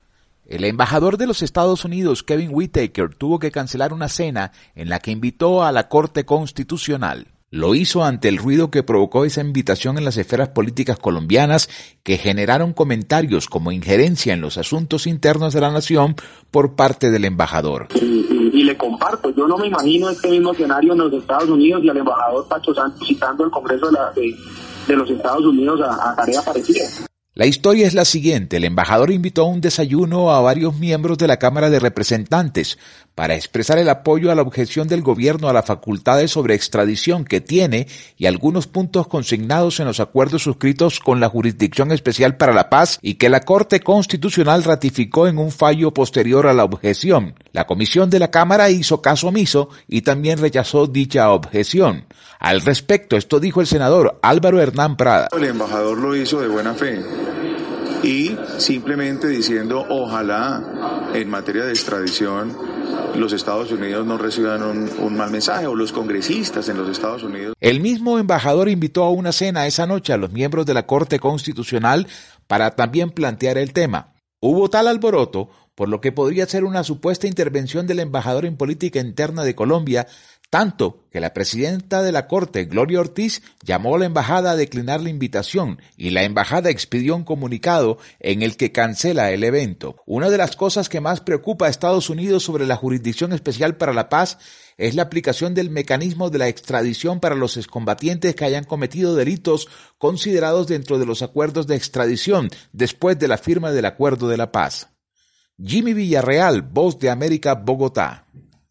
VOA: Informe desde Colombia